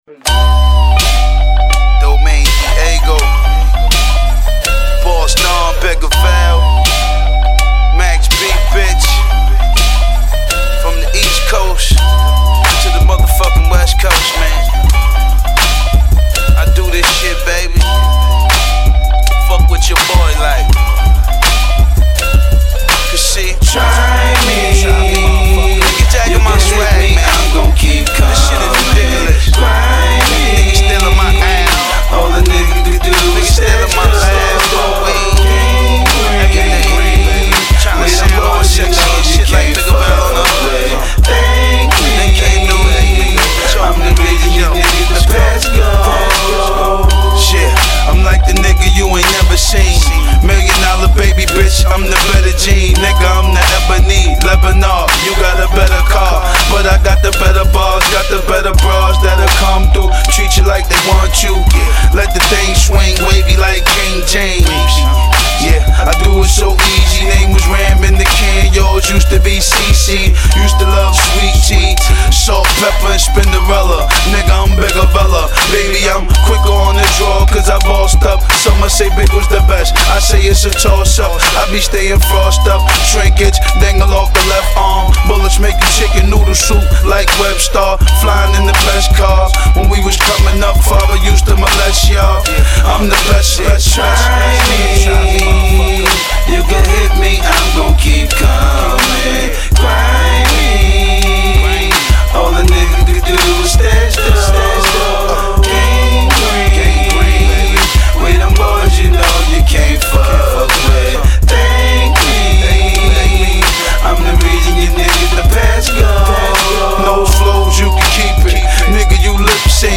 hip pop